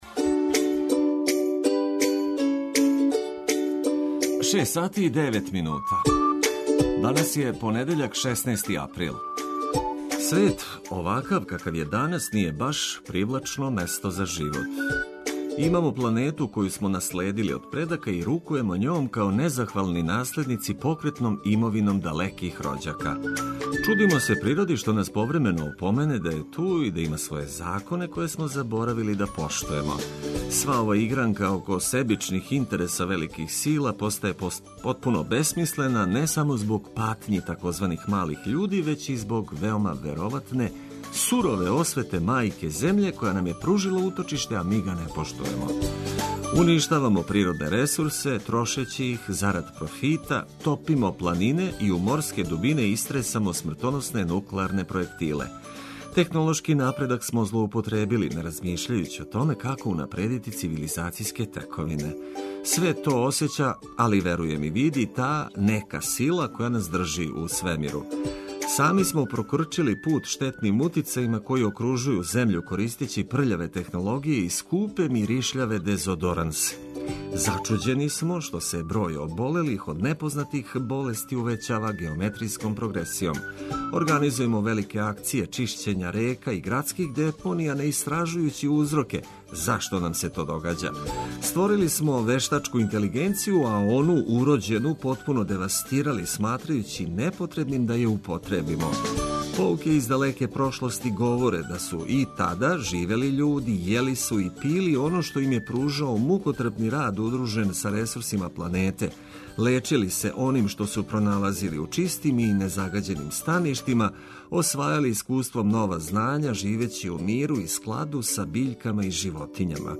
Почињемо радно, али уз музику и добро расположење.